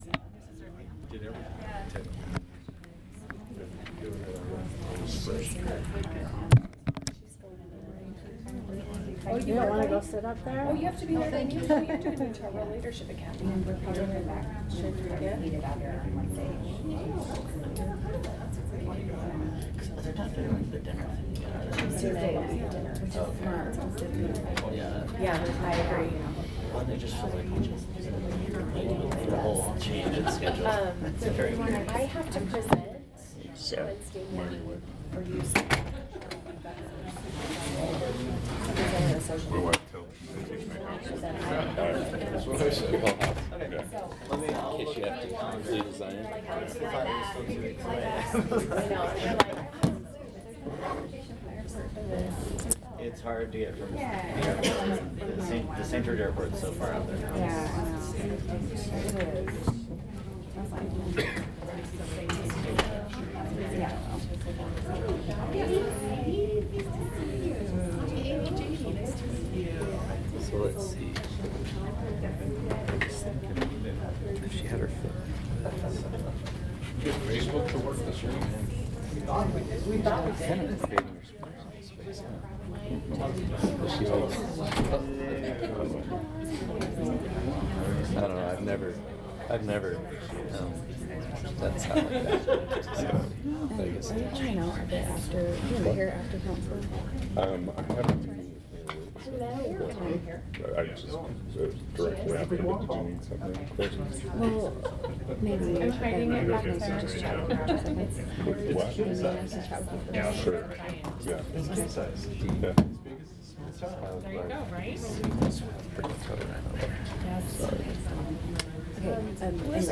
Meeting
This meeting will be simulcast via Webex so the public and members of the Board may participate electronically.
2001 S State Street Council Conference Room N2-800